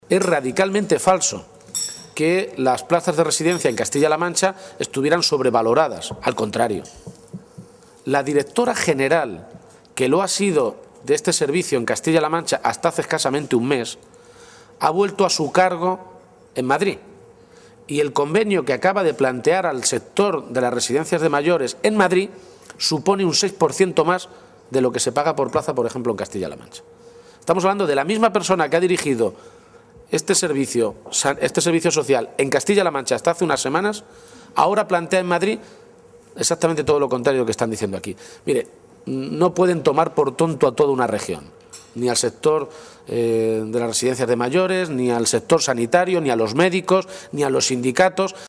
García-Page hacía estas declaraciones en un desayuno informativo con representantes de los medios de comunicación en Ciudad Real, donde ha abordado diferentes asuntos de actualidad.
Cortes de audio de la rueda de prensa